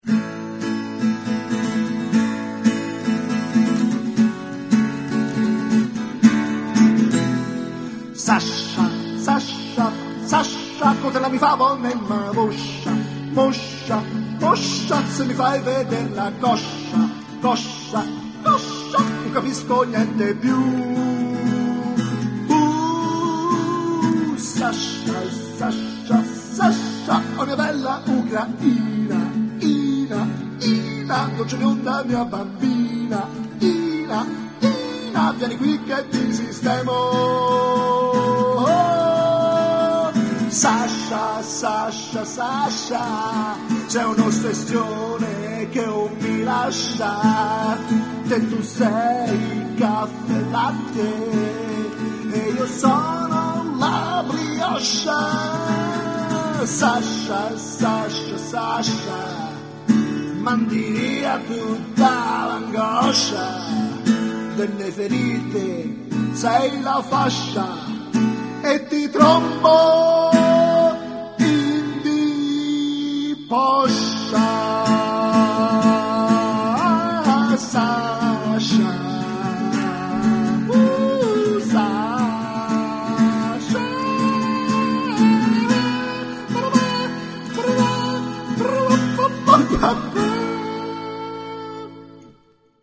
una canzone d'amore
una piccola filastrocca